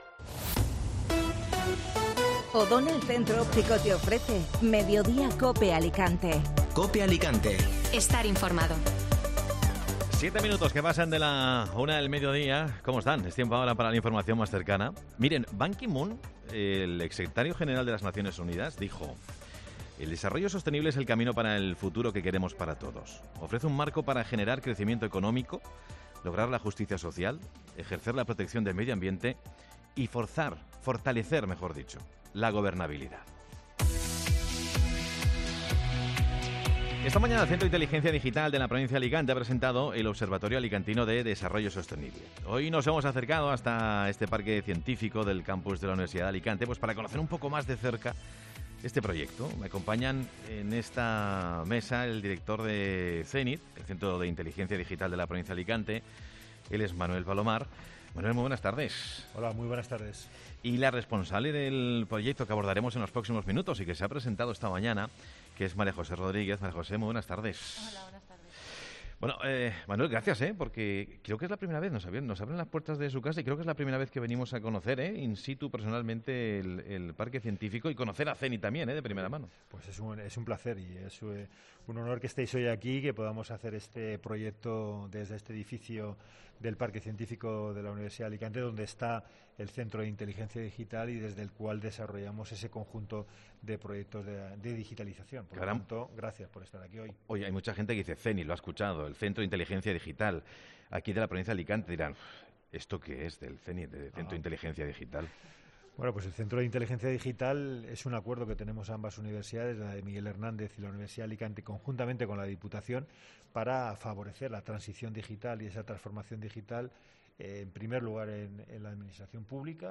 Mediodía COPE desde el Observatorio Alicantino de Desarrollo Sostenible que impulsa el CENID